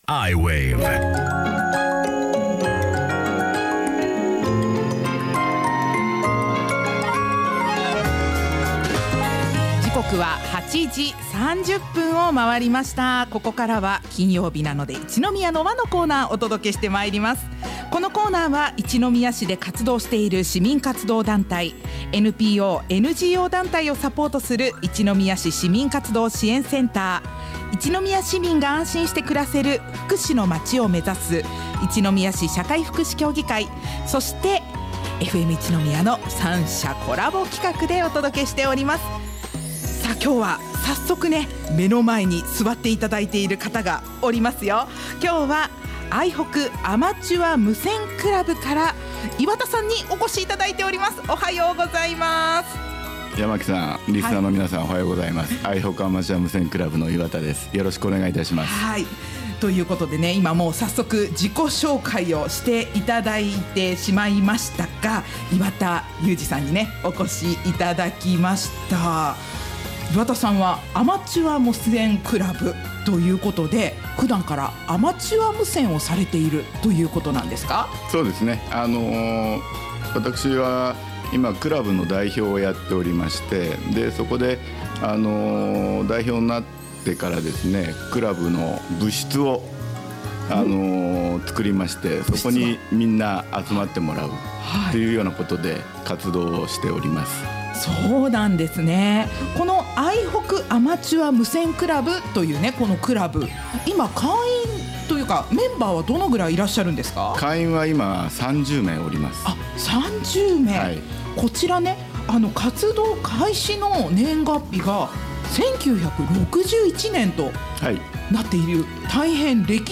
i-wave (FMいちのみや) インタビュー